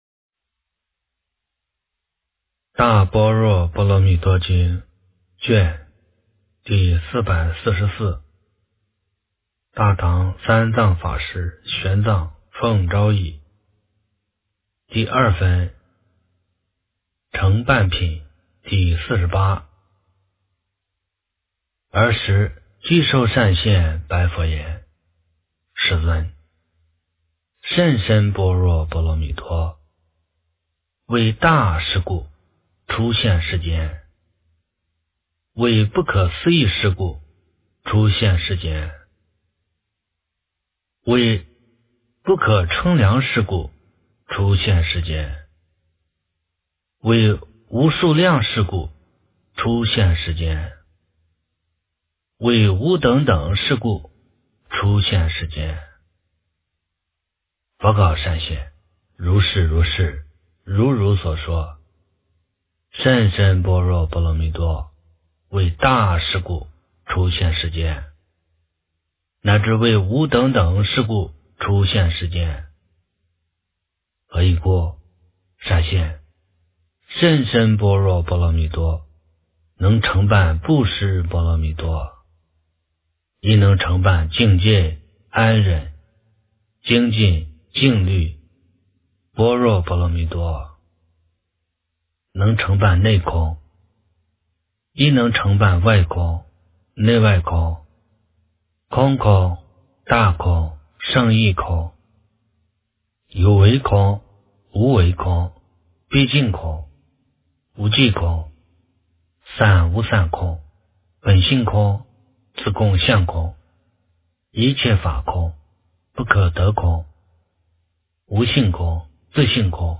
大般若波罗蜜多经第444卷 - 诵经 - 云佛论坛